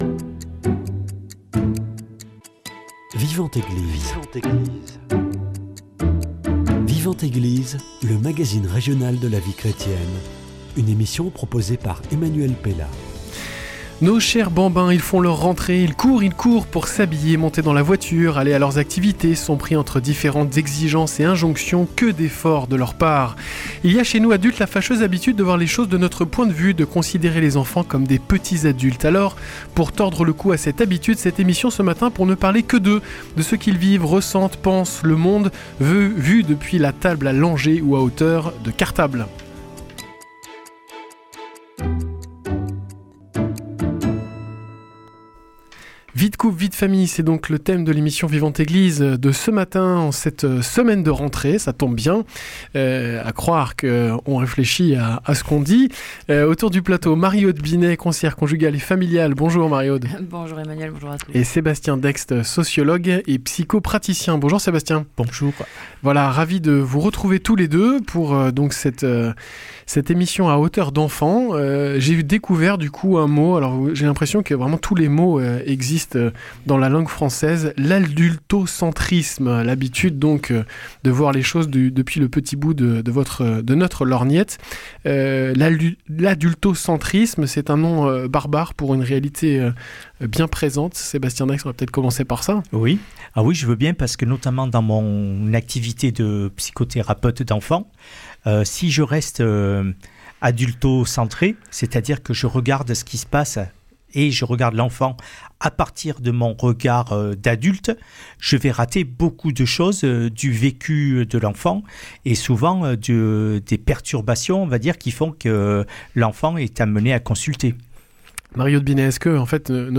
conseillère conjugale et familiale
sociologue et psychopraticien.